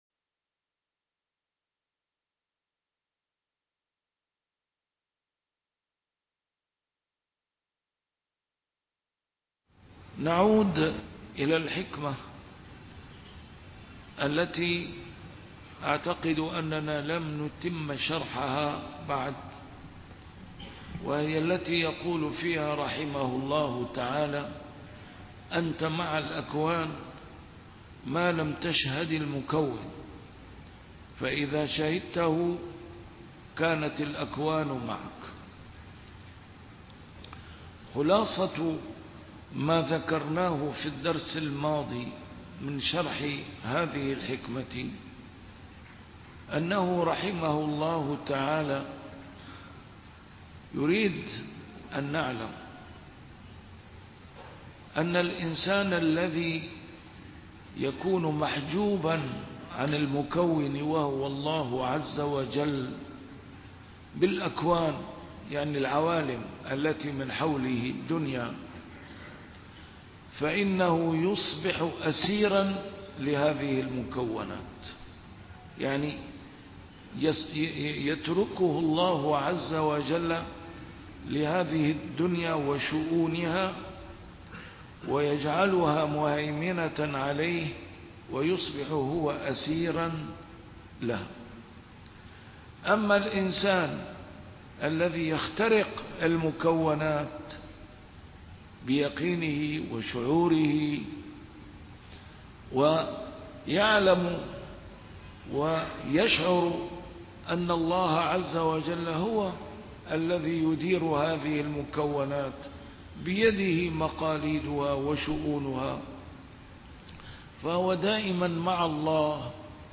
A MARTYR SCHOLAR: IMAM MUHAMMAD SAEED RAMADAN AL-BOUTI - الدروس العلمية - شرح الحكم العطائية - الدرس رقم 272 شرح الحكمة رقم 248